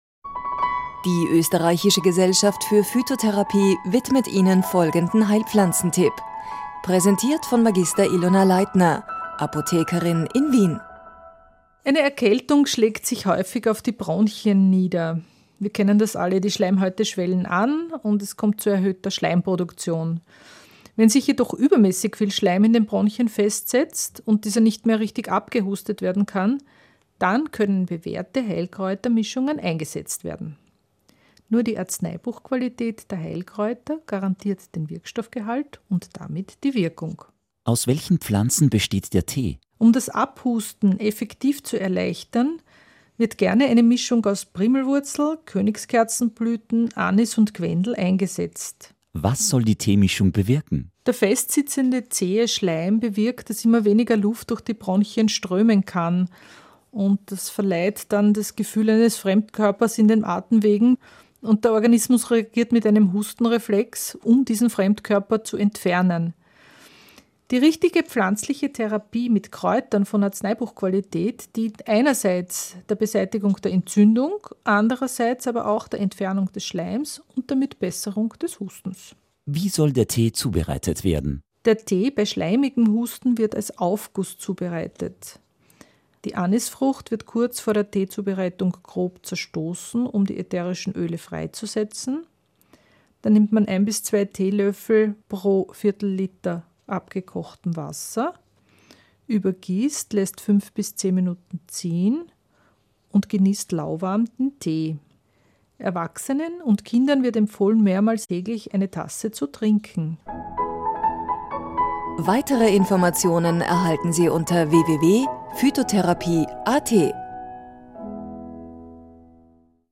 auf radio klassik Stephansdom.